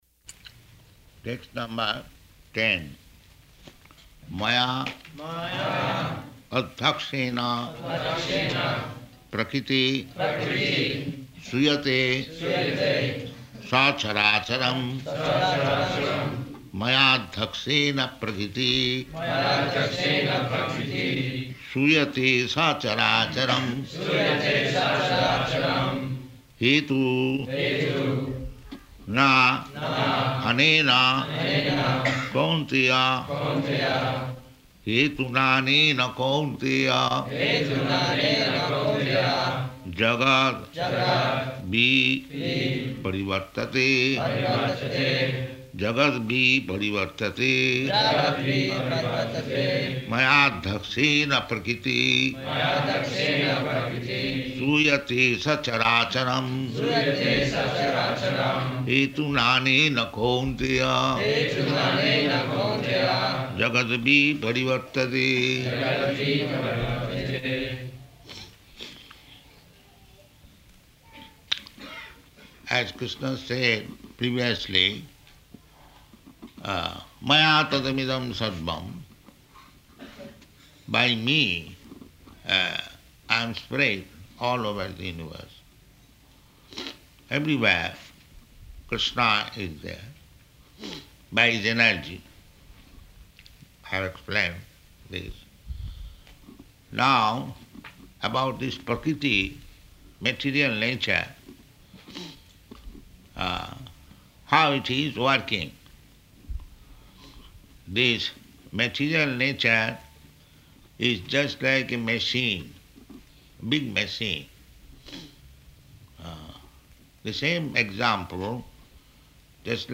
Location: Melbourne
[devotees repeat word for word, then line by line]